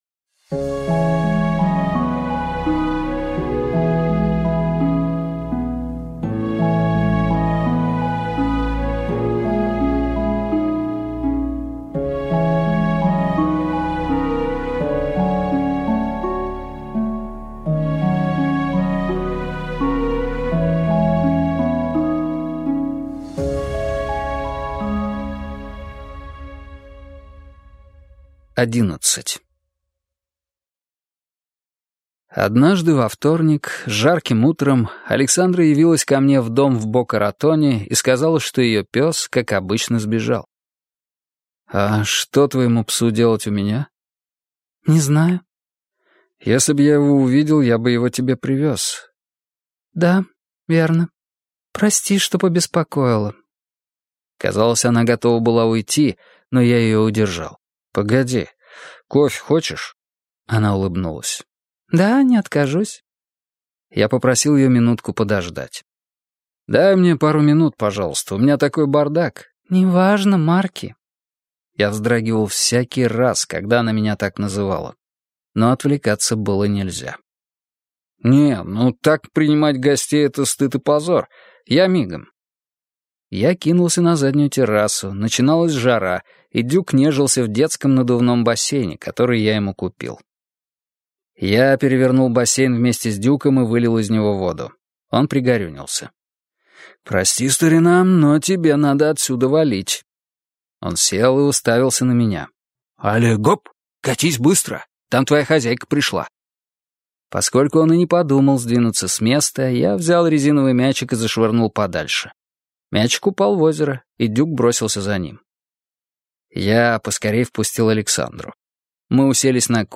Аудиокнига Книга Балтиморов - купить, скачать и слушать онлайн | КнигоПоиск